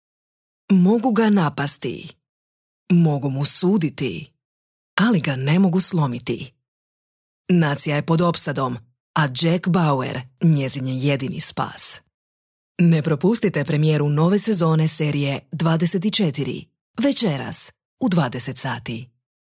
Njezin glas karakterizira uglađena, senzualna i umirujuća boja te svestrani vokalni stil koji se kreće u rasponu od toplog, senzualnog do razigranog, autoritativnog, korporativnog, energičnog.
Tv najava
Tv_najava.mp3